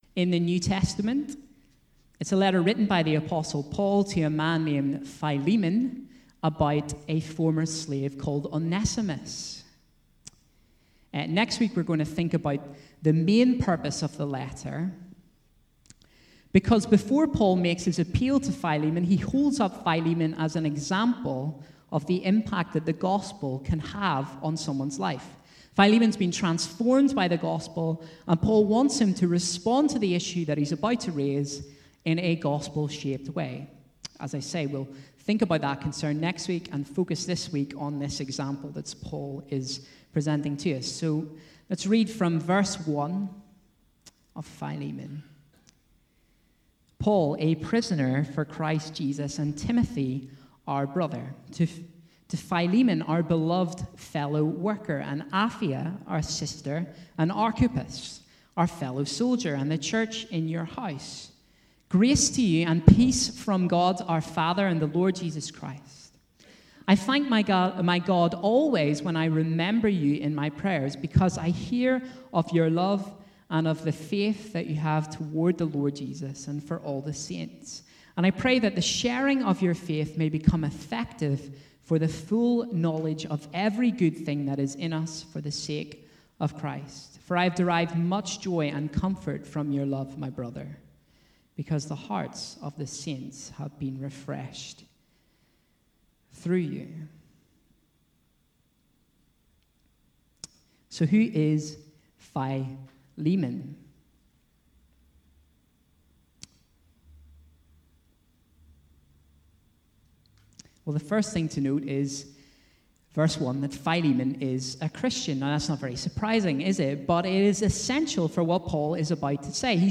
A message from the series "Philemon."